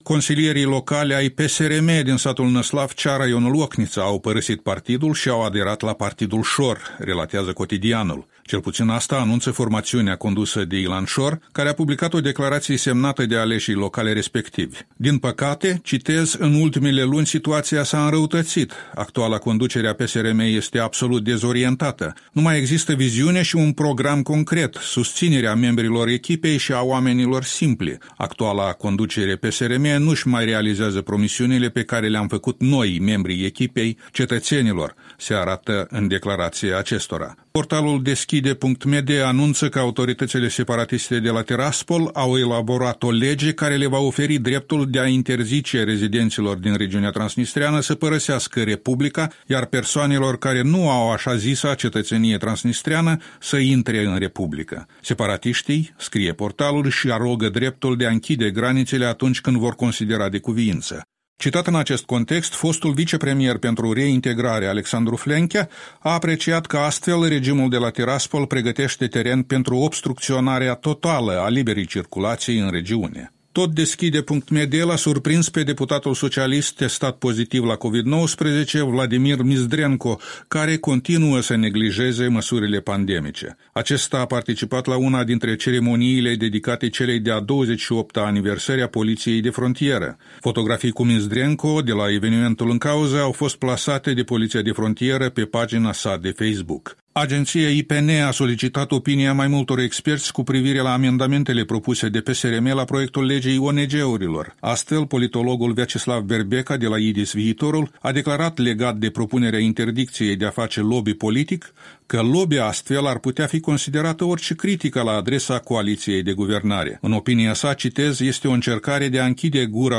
Revista presei matinale la radio Europa Liberă.